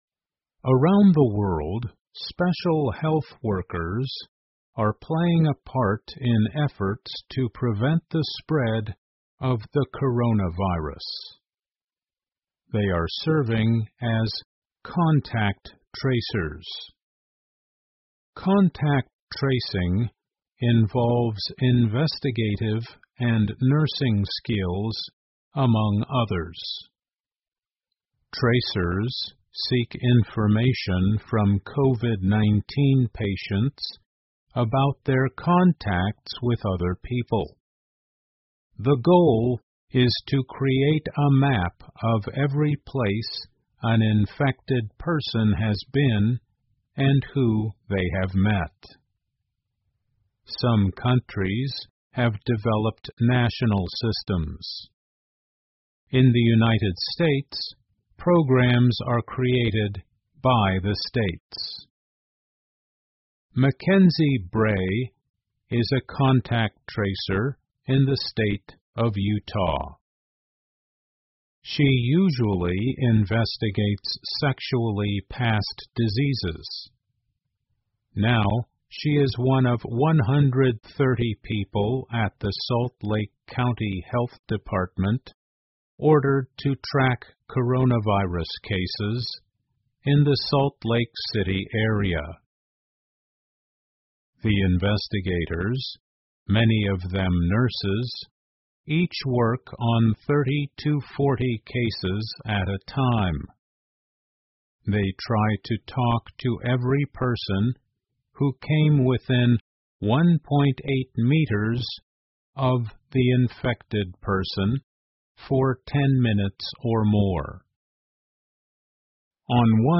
美国公共电视网PBS高端访谈节目，其所提供的高质量节目与教育服务，达到媒体告知(inform)、启发(inspire)与愉悦(delight)的社会责任。